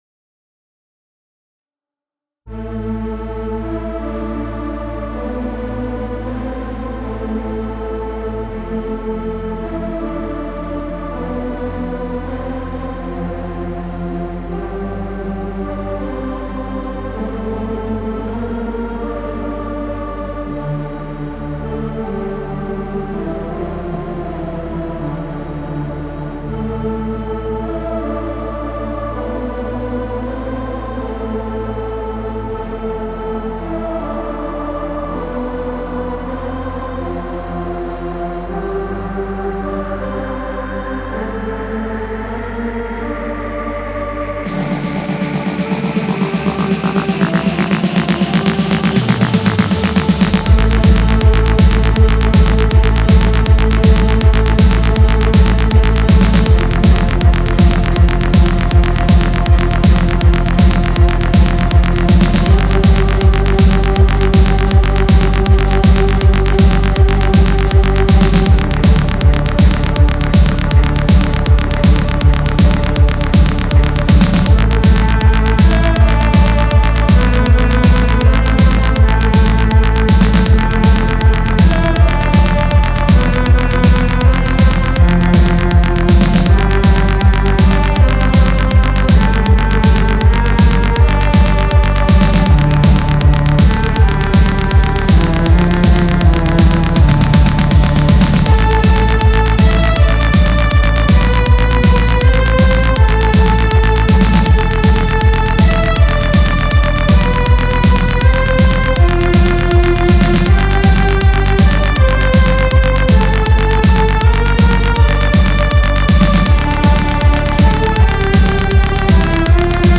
Elektronischer Genremischmasch mit Orchesterelementen in 160 BPM
ist so einiges mit dabei Chöre, Streicher, Orgel, Chembalo, Supersaw, Pads, Arps Psytrance Bassline, Tomrolls, Snare mit gated Reverb und Hardstyle/Rawstyle Kick am Ende.